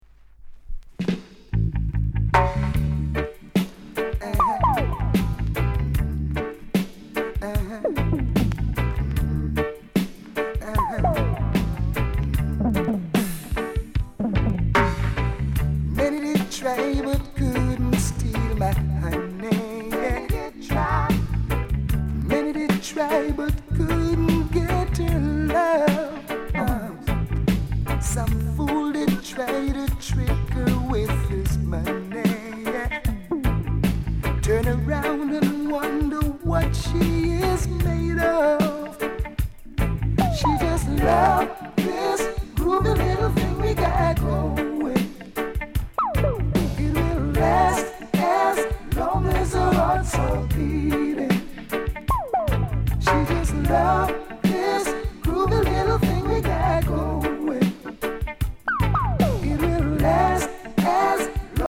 RIDDIM HIT